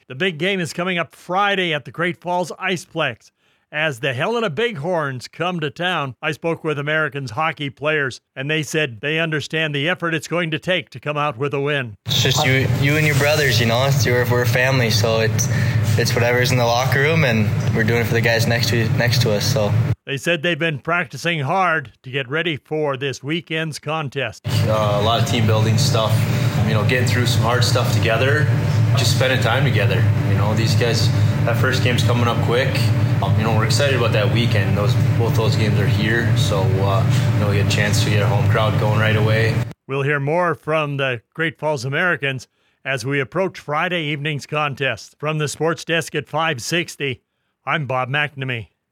560AM KMON: Weekly Radio Interview